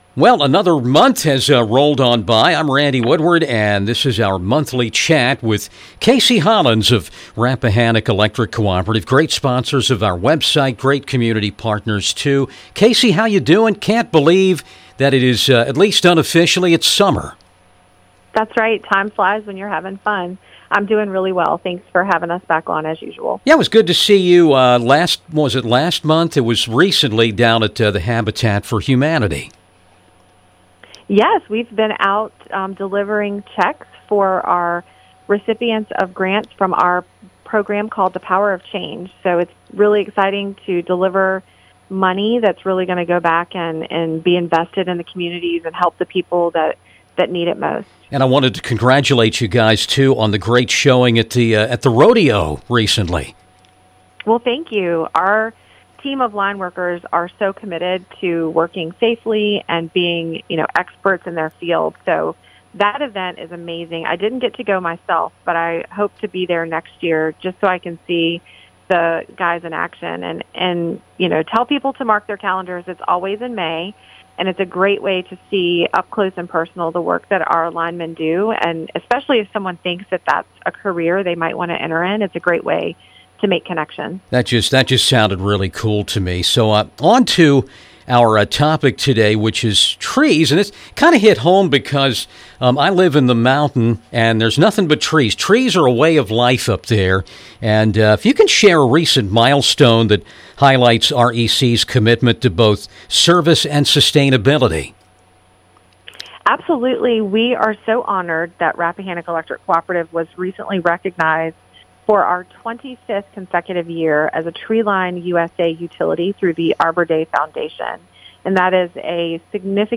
joined 95.3 The River talking about REC receivimg the prestigous Tree Line USA designation for the 25th year in a row. REC's certified arborists and foresters play a key role in this mission. Each year, they partner with local communities to plant and give away dozens of trees, fostering greener spaces and stronger relationships.